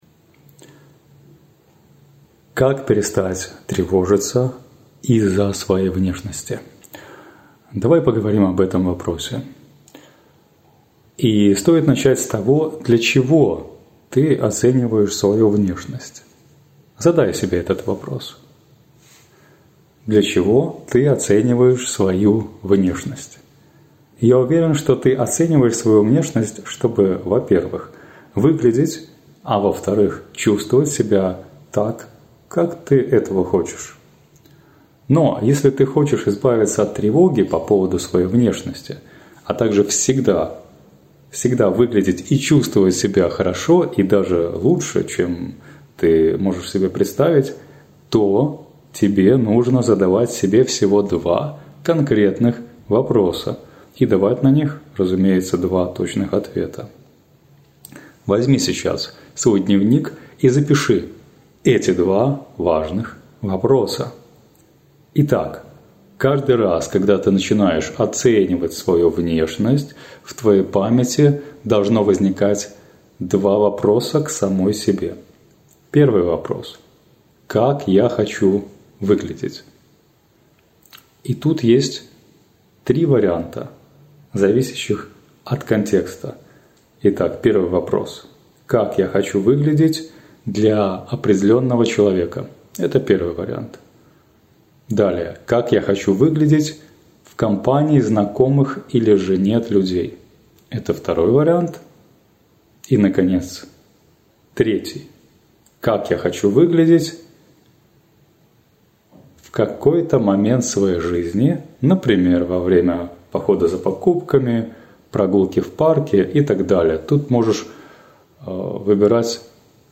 Голосовая заметка